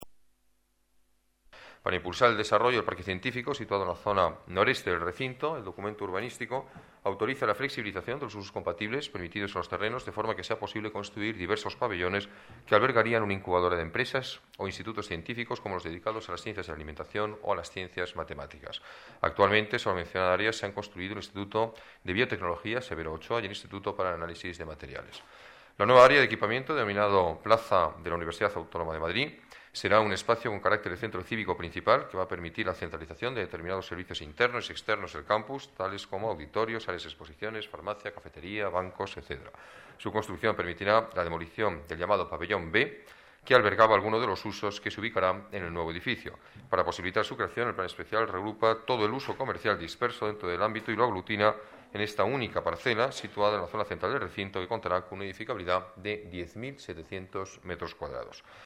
Nueva ventana:Declaraciones del alcalde de Madrid, Alberto Ruiz-Gallardón: Un parque Científico y Centro Cívico para la Universidad Autónoma